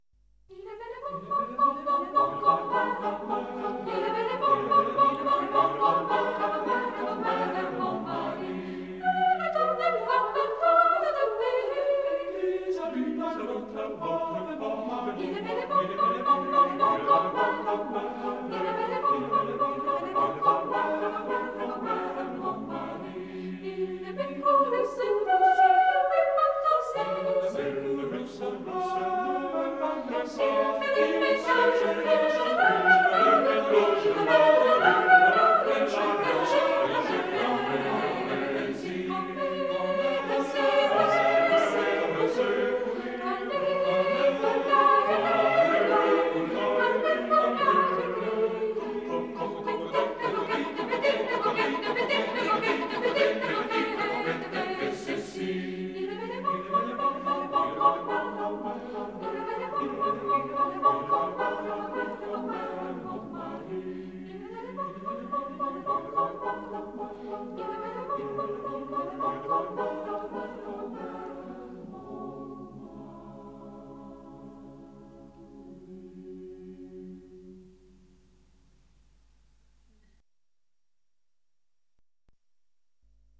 „Il est bel et bon” (Passereau) de pe albumul „MADRIGAL chamber chorus, conductor: MARIN CONSTANTIN” interpretat de Corul Naţional de Cameră MADRIGAL – dirij. MARIN CONSTANTIN.